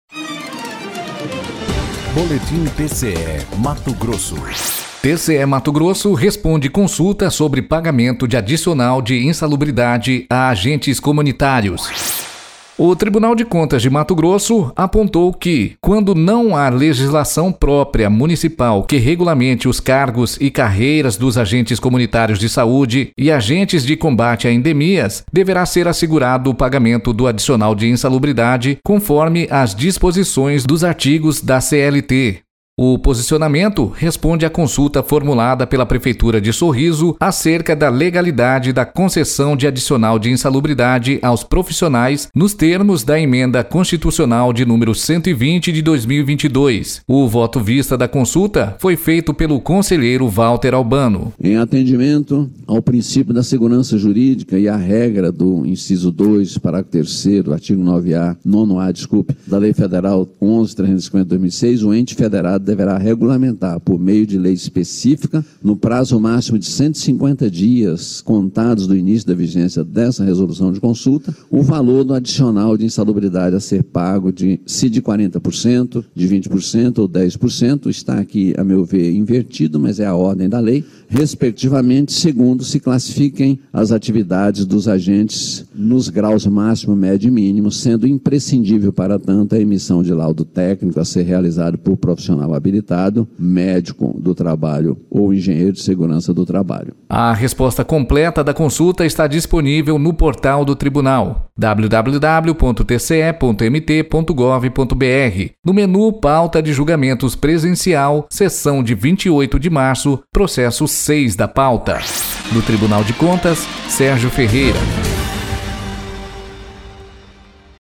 Sonora : Valter Albano – conselheiro do TCE-MT